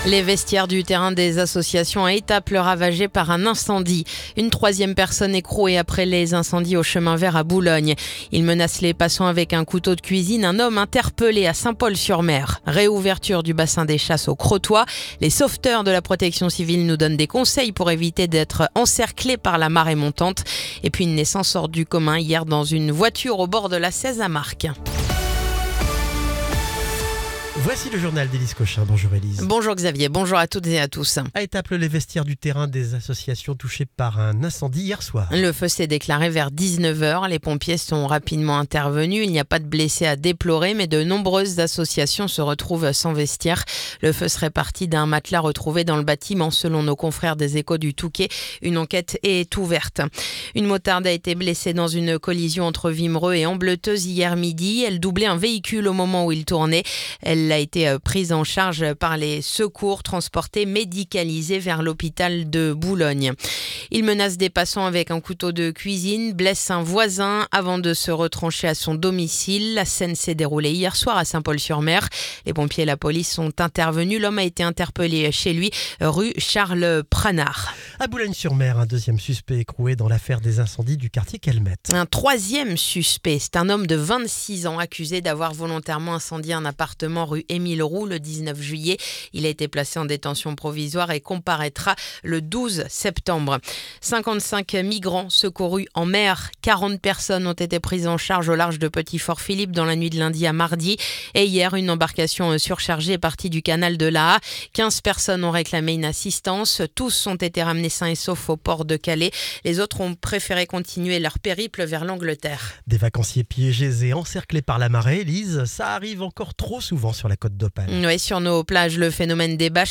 Le journal du mercredi 30 juillet